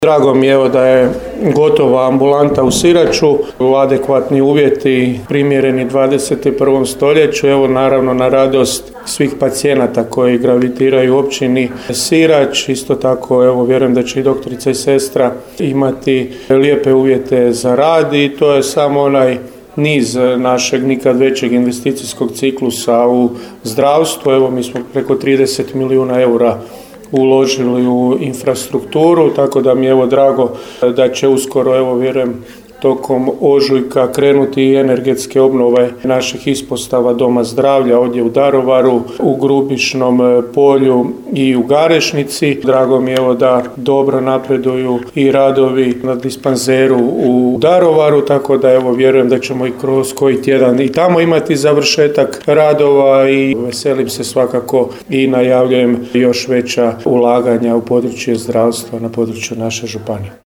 Kompletno je obnovljena liječnička i stomatološka ordinacija u Siraču. To je samo dio velikih ulaganja za unapređenje zdravstva u svakom pogledu u BBŽ istaknuo je za Radio Daruvar župan Marko Marušić: